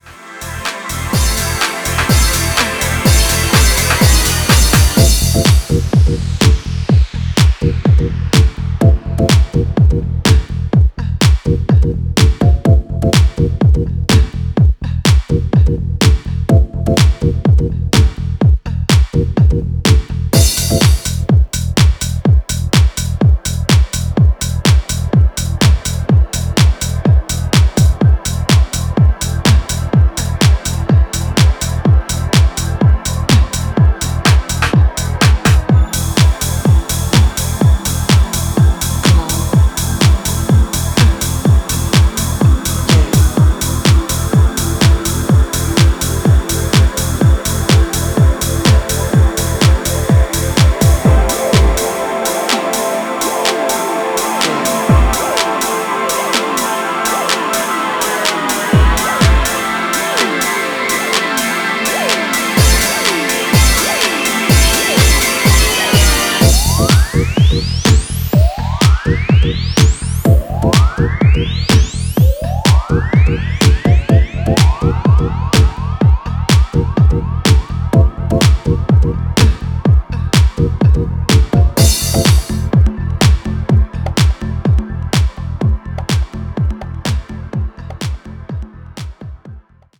ダビーなオルガンのコードやパンチの効いた909ドラム、ボンゴ・ループ等で90sニュージャージー・ハウスをオマージュした